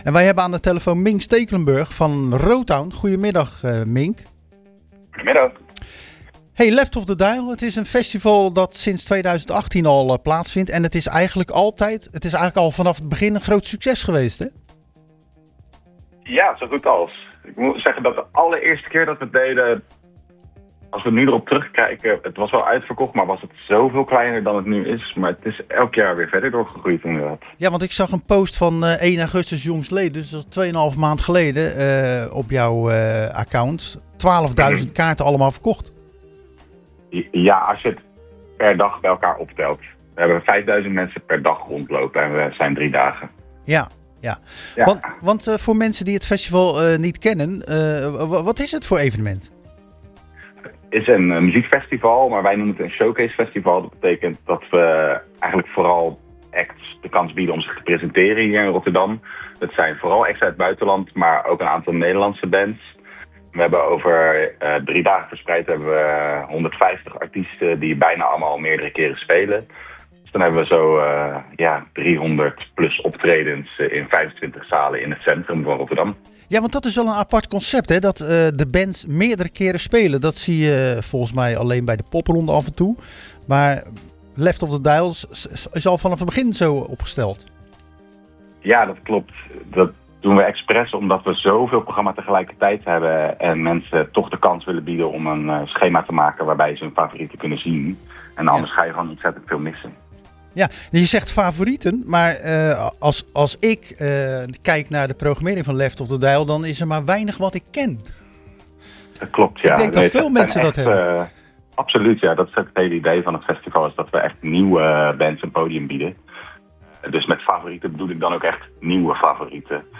Tijdens de uitzending van Zwaardvis belden we